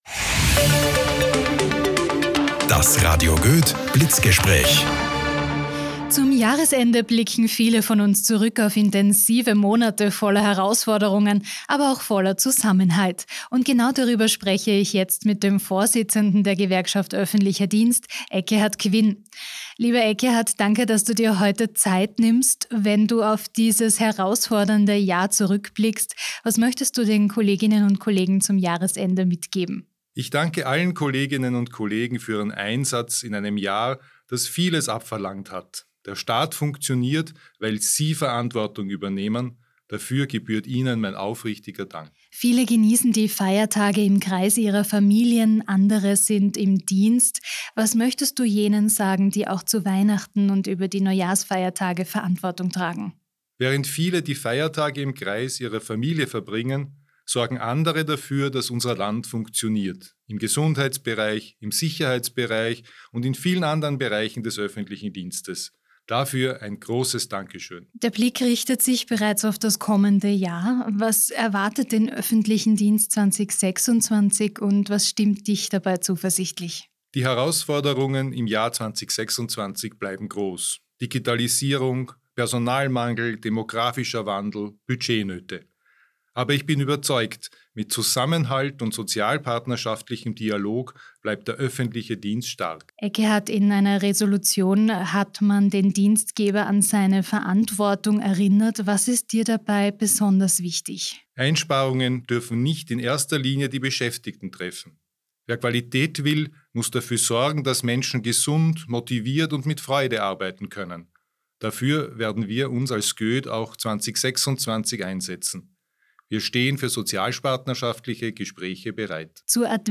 Blitzgespräch